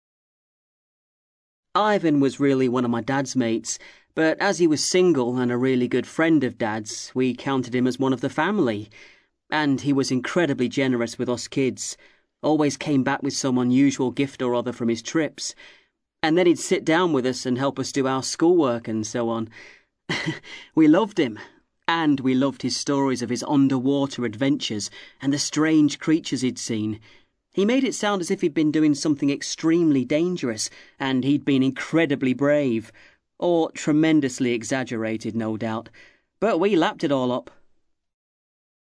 ACTIVITY 60: You will hear five short extracts in which five people are talking about a member of their family who they admire.